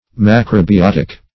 Macrobiotic \Mac`ro*bi*ot"ic\, a. [Gr.